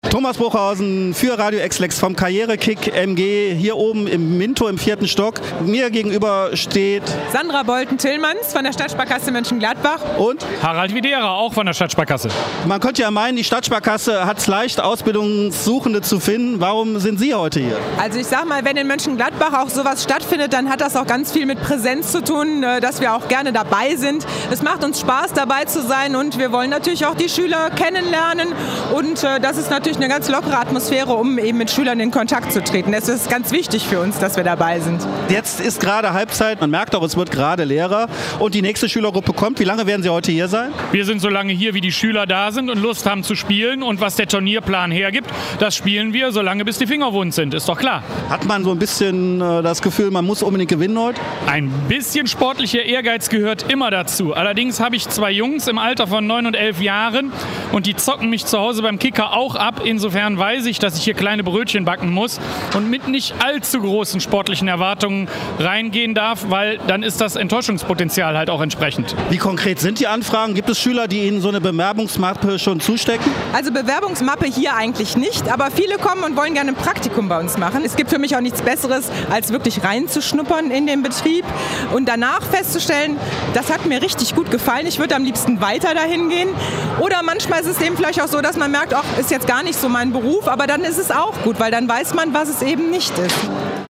Kickern mit den Azubis
Interview-SSK.mp3